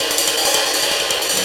Ride 13.wav